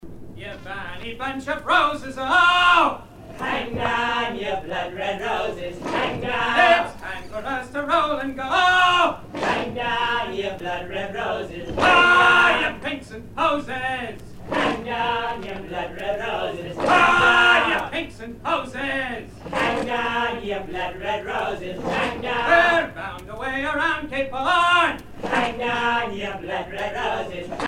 circonstance : maritimes
Pièce musicale éditée